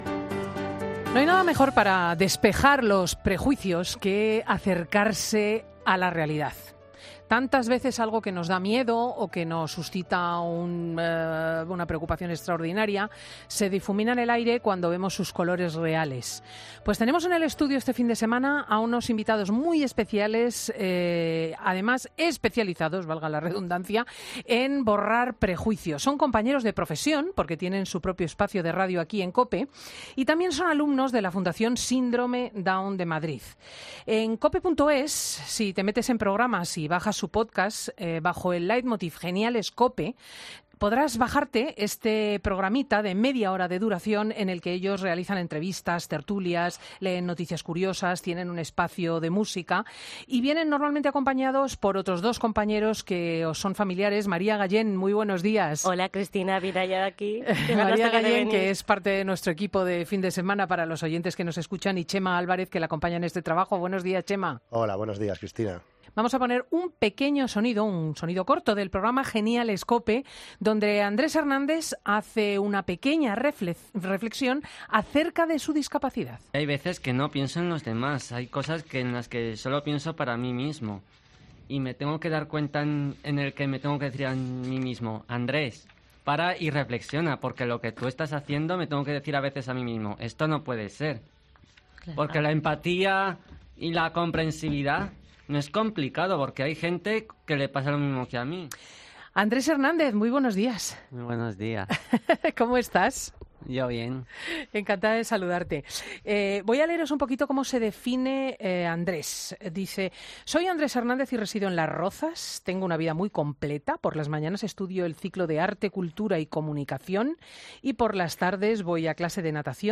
Estos jóvenes de Fundación Síndrome de Down Madrid han ido a Fin de Semana con Cristina López Schlichting para contarles cómo es este espacio en podcast. En Fin de Semana nos han explicado qué es el síndrome de down, cómo se ven ellos, qué significa tener esa discapacidad, fisicamente cómo les afecta. 00:00 Volumen Cerrar Ser genial es ser tú mismo Nos cuentan de primera mano cómo es para personas con síndrome de down hacer un programa de radio.